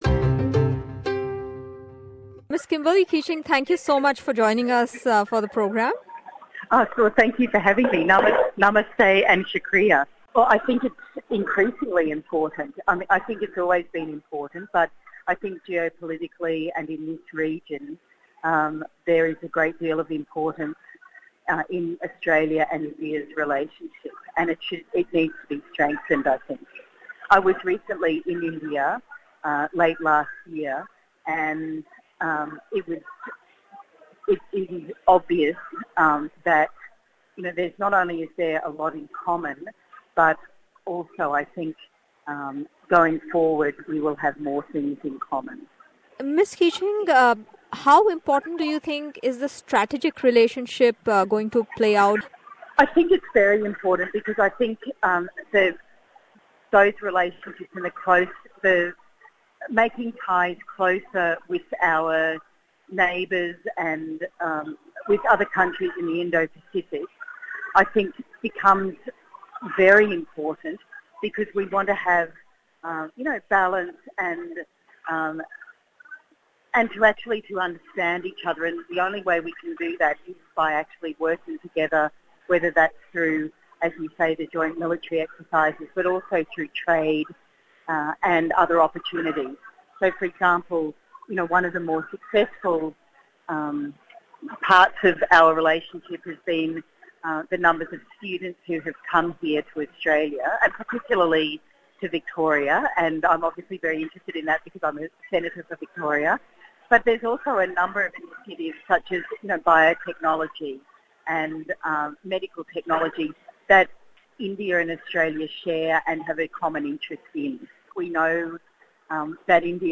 Australian Federal Senator Kimberly Kitching spoke to us on 26th January about India-Australia relations. The Labour Senator from Victoria told us that that she feels India is Incredible!